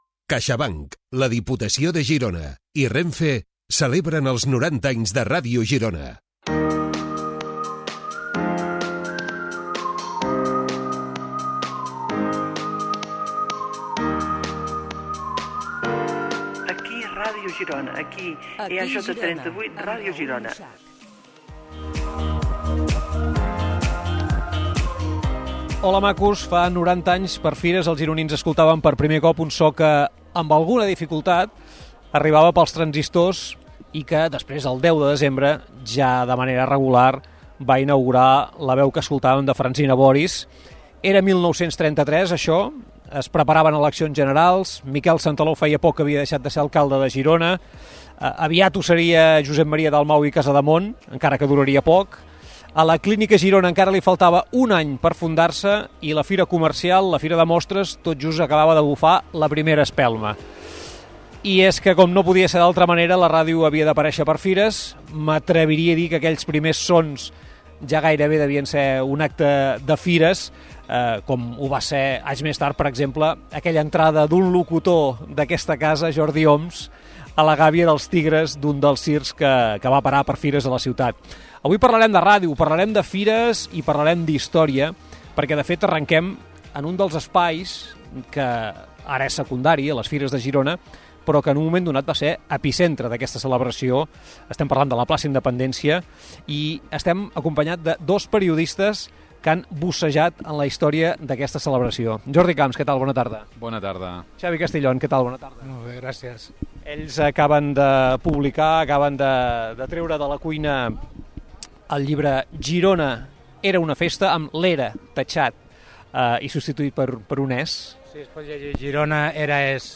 Publicitat. Espai fet des de la Plaça de la Independència. Record dels 90 anys de Ràdio Girona.
Info-entreteniment